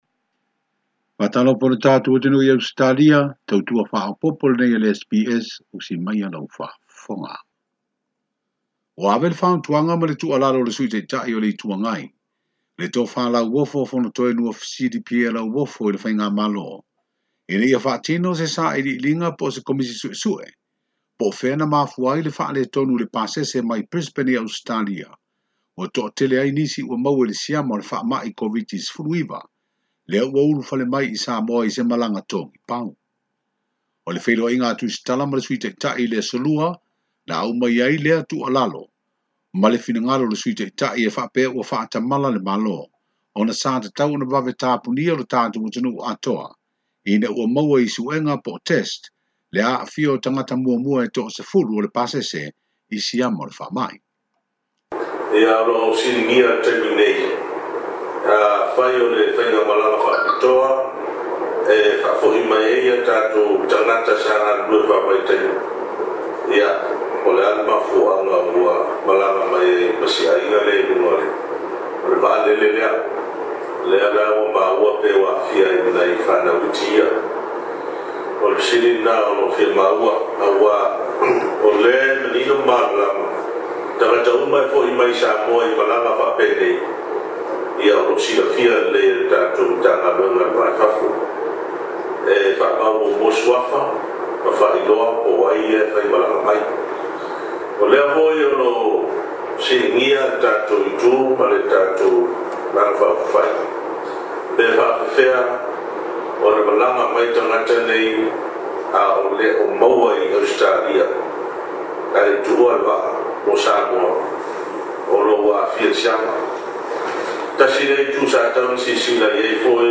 Ripoti faapitoa o le KOVITI-19 i Samoa mo le Aso Faraile 28 Ianuari.